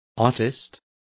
Complete with pronunciation of the translation of artists.